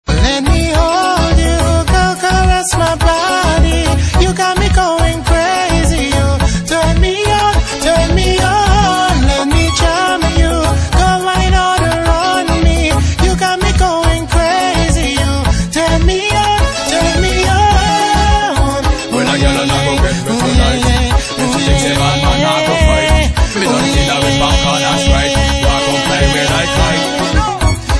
• Category Country